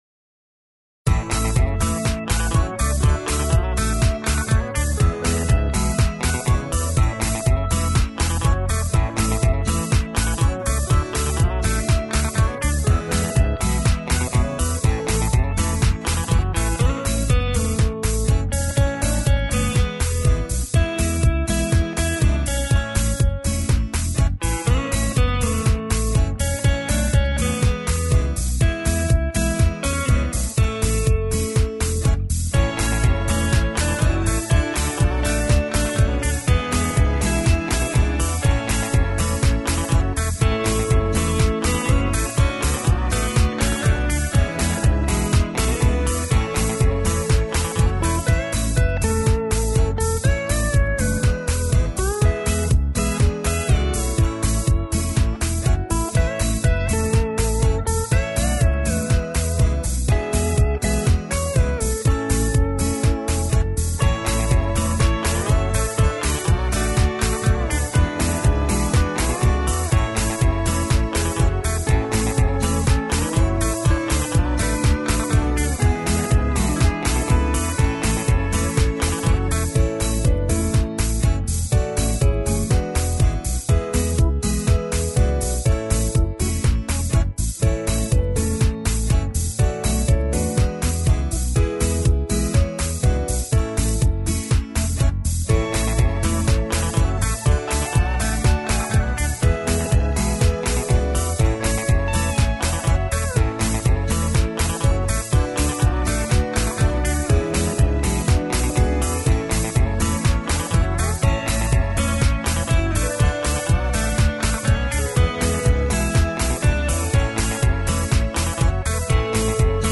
SC = Singing Call